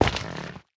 Minecraft Version Minecraft Version latest Latest Release | Latest Snapshot latest / assets / minecraft / sounds / block / netherrack / step6.ogg Compare With Compare With Latest Release | Latest Snapshot
step6.ogg